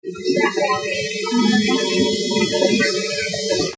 It sounded like a snippet of children chanting a rhyme.
I found that noise filtering cuts down the hiss and rumble but adds the weird overtones bubbling in the background.
snap_taffy_filtered.mp3